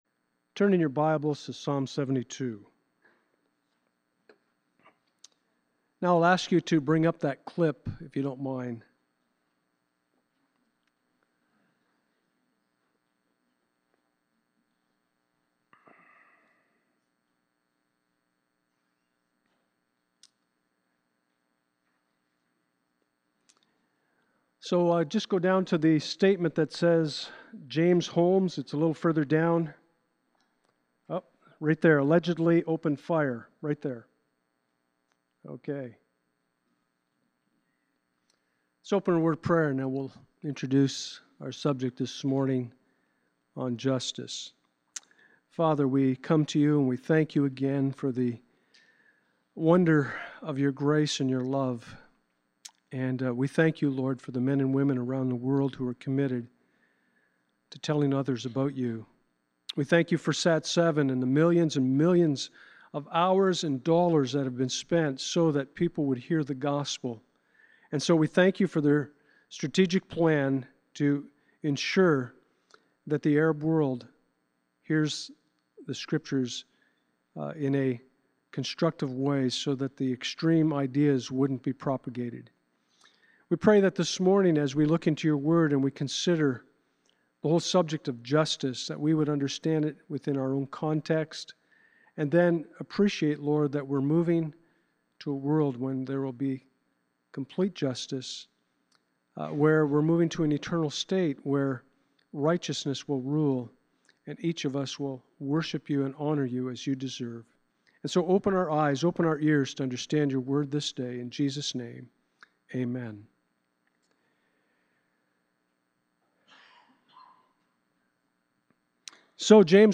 Micah 6:8-Psalm 6:8 Service Type: Sunday Morning « Saved by Grace Though Faith Living by Faith